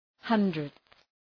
Προφορά
{‘hʌndrıdɵ} (Αριθμός) ● εκατοστός